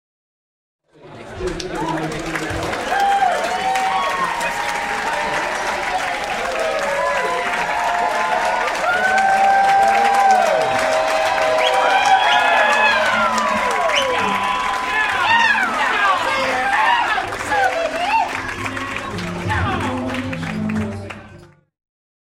Гости встречают аплодисментами жениха и невесту